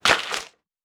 Generic Net Swoosh.wav